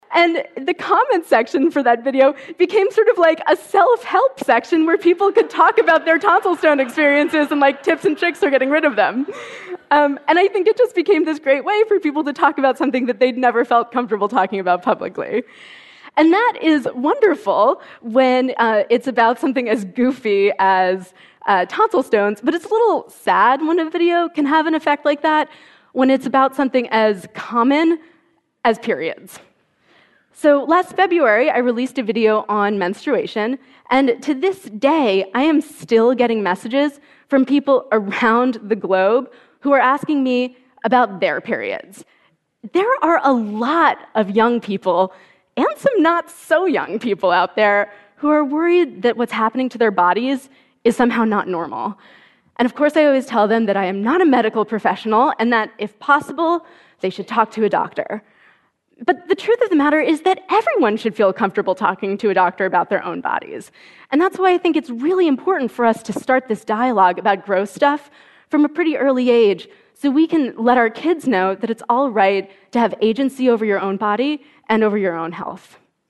TED演讲:为什么应该喜爱'恶心'的科学(6) 听力文件下载—在线英语听力室